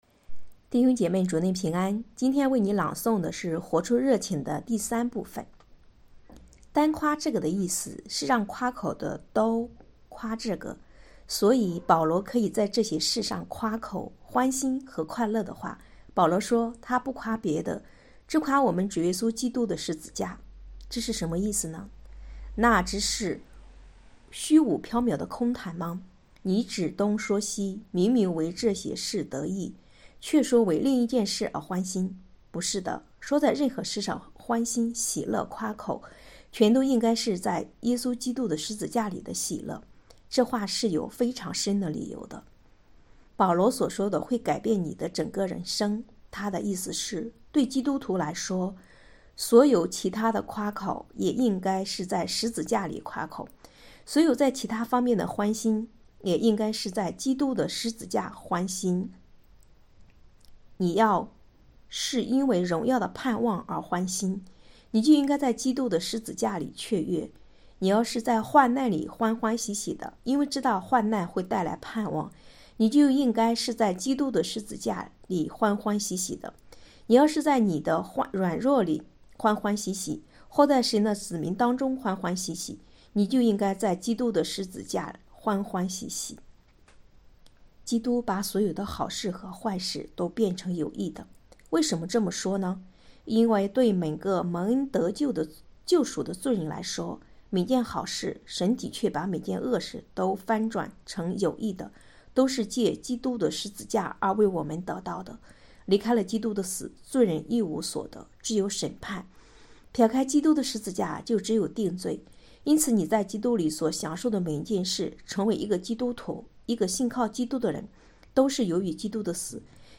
2023年9月14日 “伴你读书”，正在为您朗读：《活出热情》 音频 https